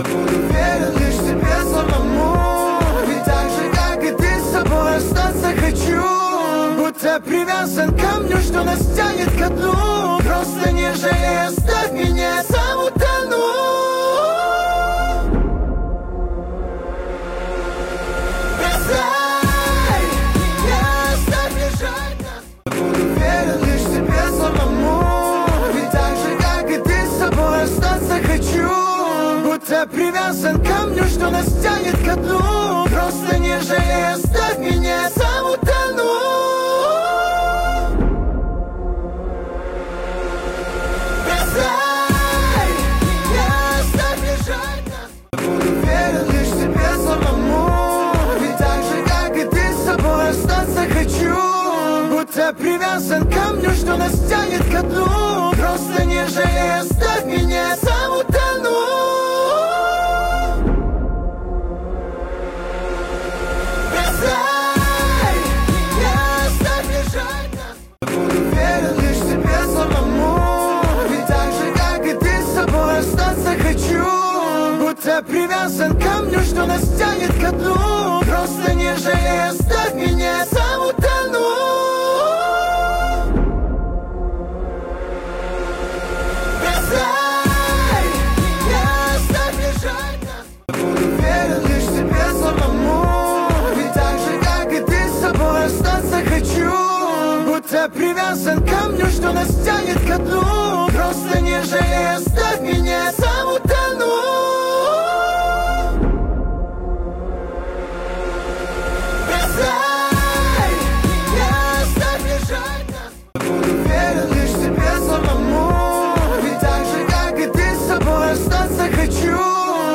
Поп музыка, Новинки